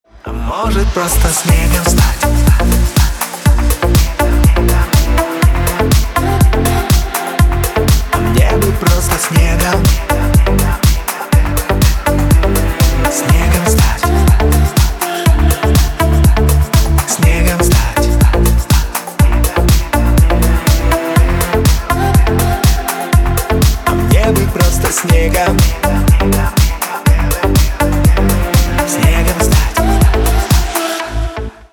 • Песня: Рингтон, нарезка
• Категория: Русские рингтоны